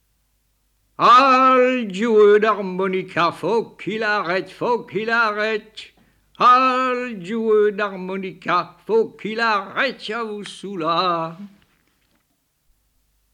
Type : chanson de lutte sociale ou de résistance | Date : Septembre-novembre 1972